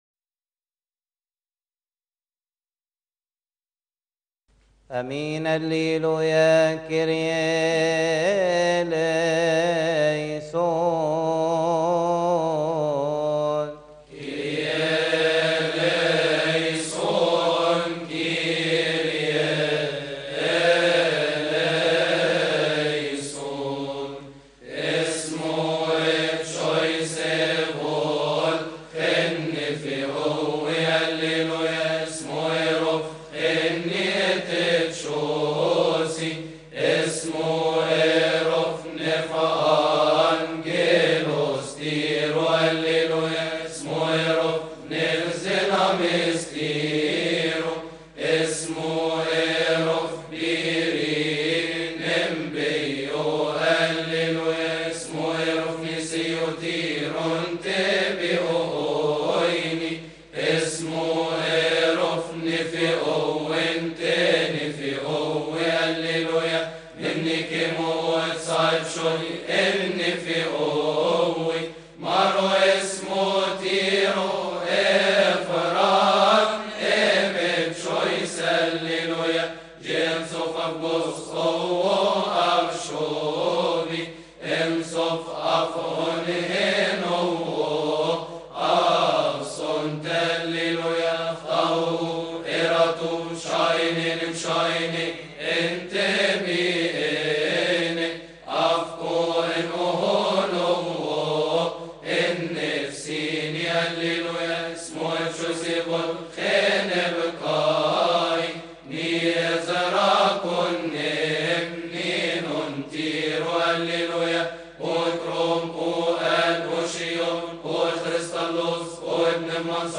لحن إسمُّو إىَ أبتشويس